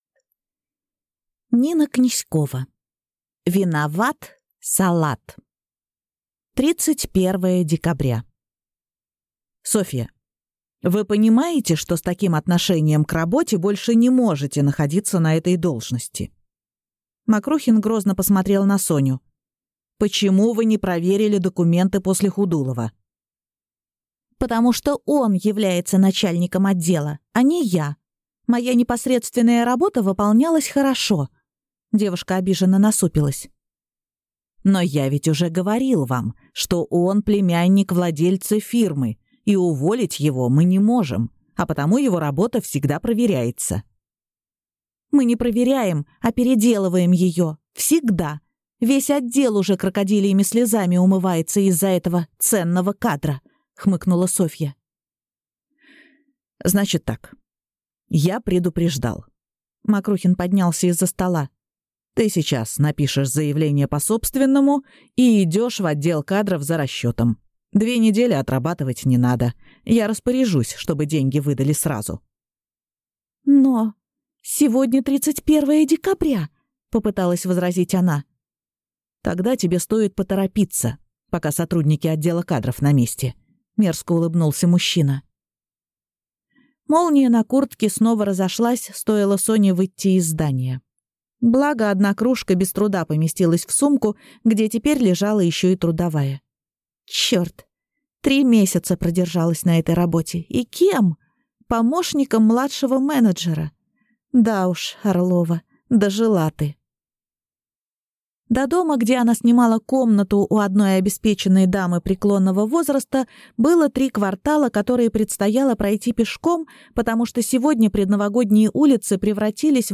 Аудиокнига Виноват салат | Библиотека аудиокниг